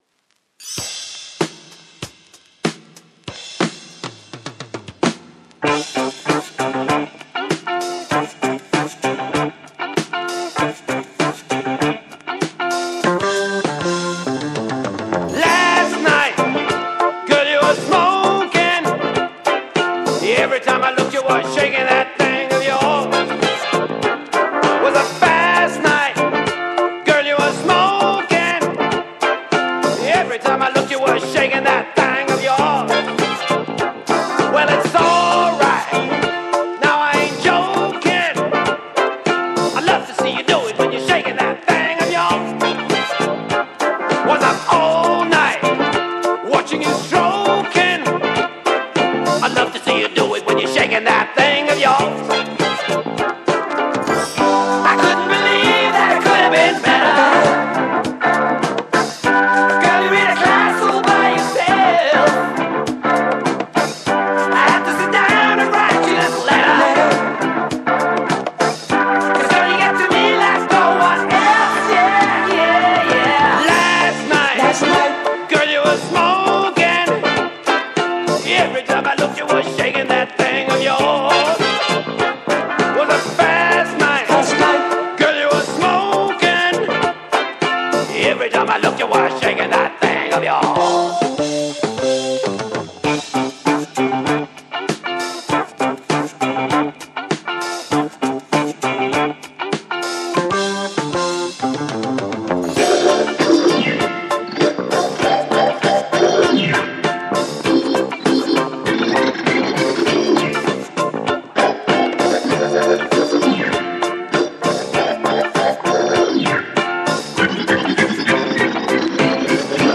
Small French 80s funk single, not so easy to find.